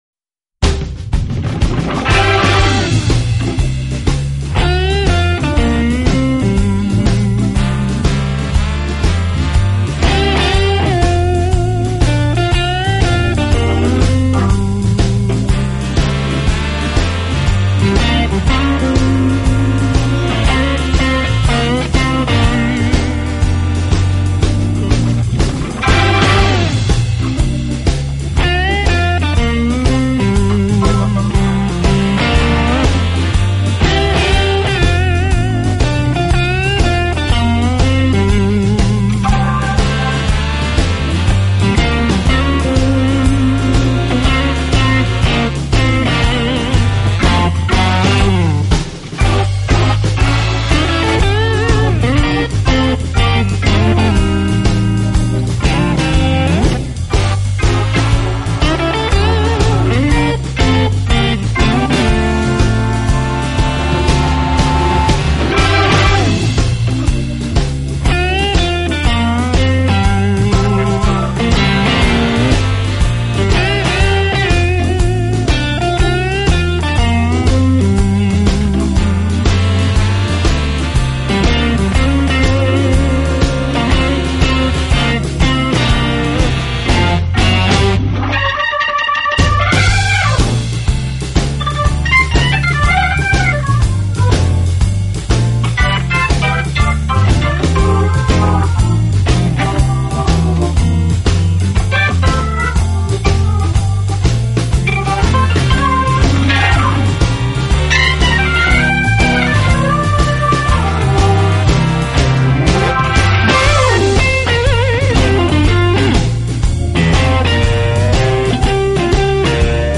爵士吉他